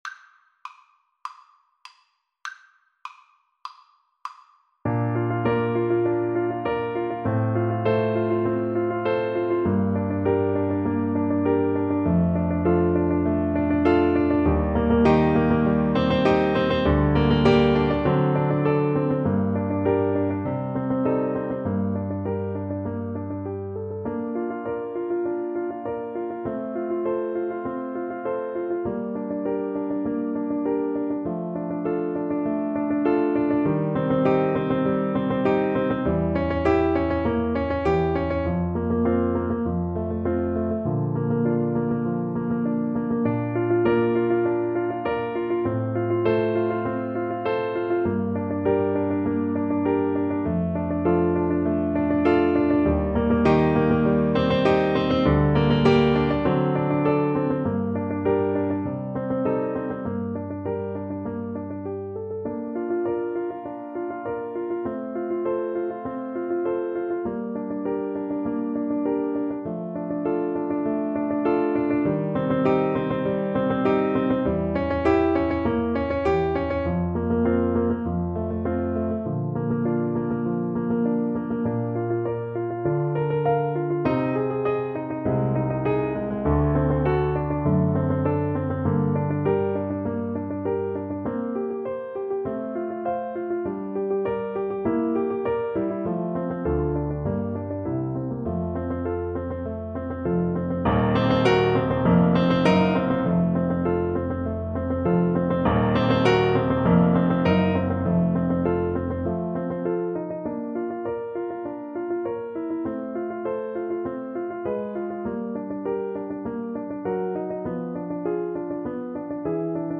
Allegro moderato (View more music marked Allegro)
Classical (View more Classical Violin Music)